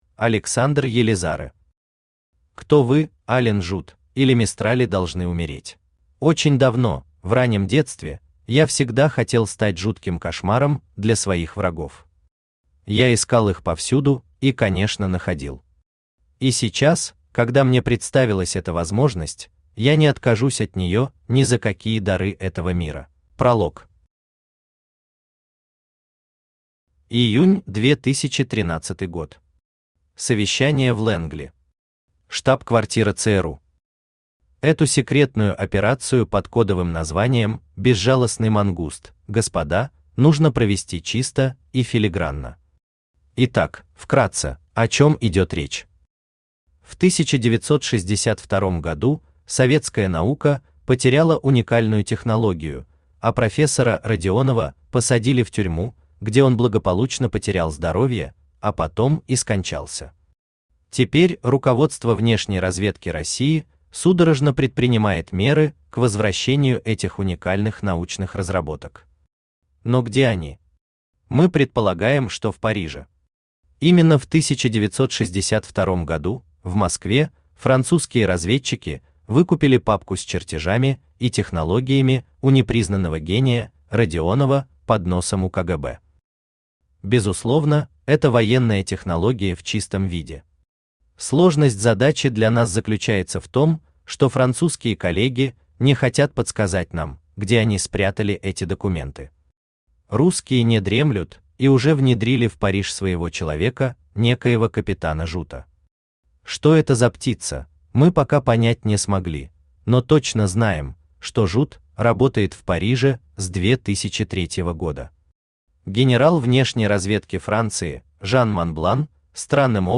Aудиокнига Кто вы – Ален Жут, или Мистрали должны умереть Автор Александр Елизарэ Читает аудиокнигу Авточтец ЛитРес.